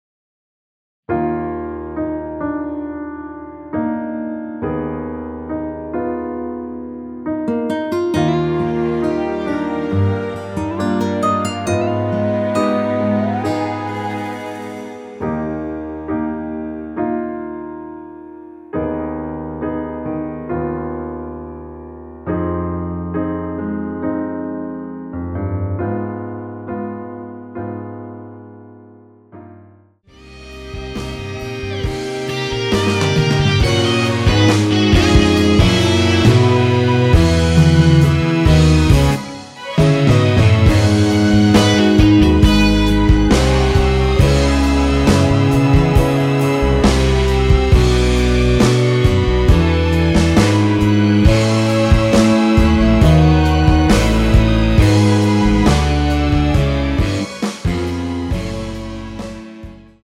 원키에서(+3)올린 MR입니다.
Eb
앞부분30초, 뒷부분30초씩 편집해서 올려 드리고 있습니다.
중간에 음이 끈어지고 다시 나오는 이유는